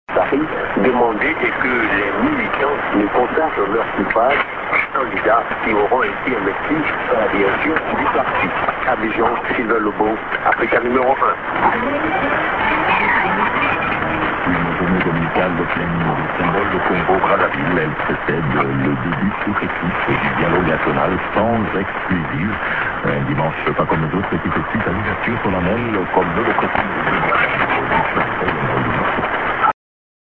->ANN(men)->